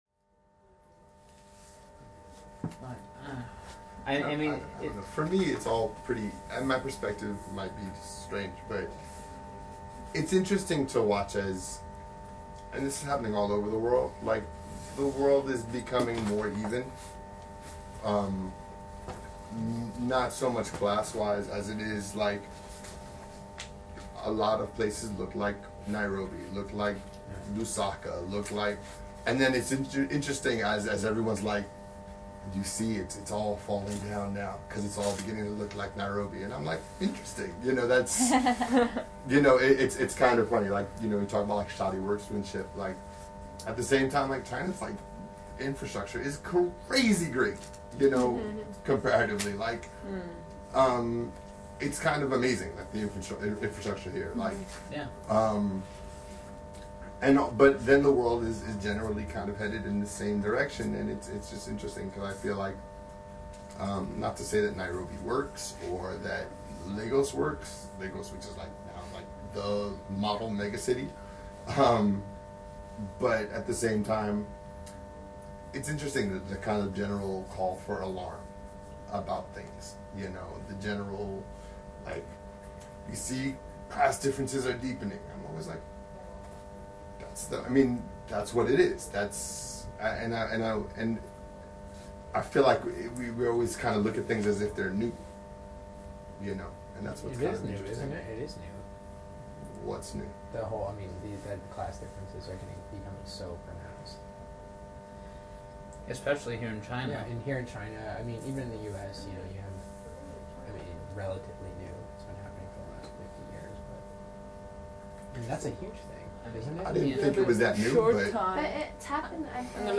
the notes below are simple tags to help you find your way —- the meeting in full is recorded below; skim through and hopefully find something interesting for you, or try playing them all at once and listen to the signal-to-noise ratio…